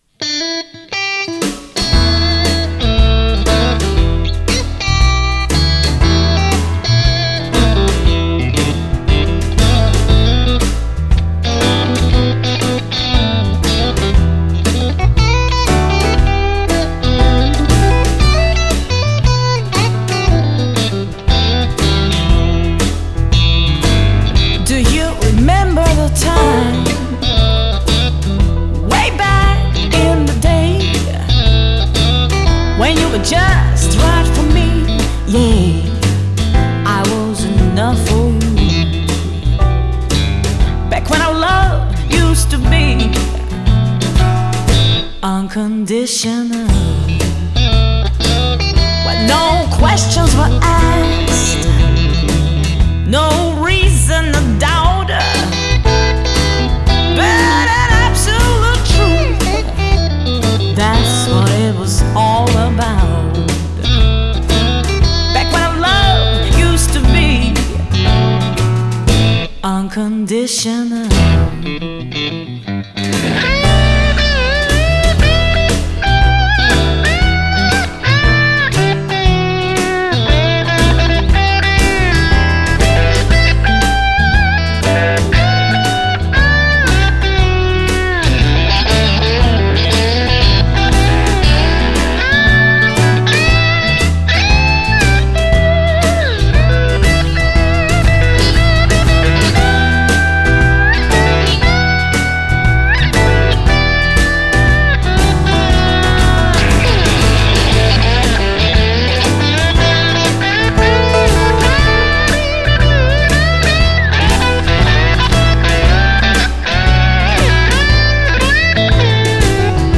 Musiktheater Piano Dortmund